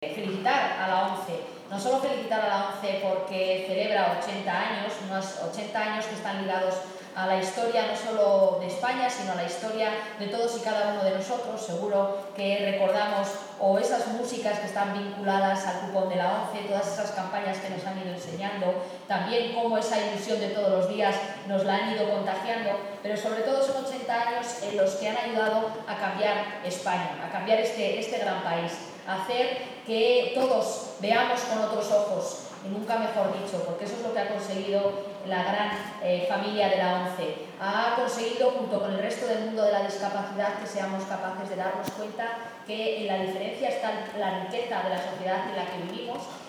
Galas en Logroño y Santander
Como anfitriona del acto, la alcaldesa Cuca Gamarra quiso felicitar a la ONCE por su 80 aniversario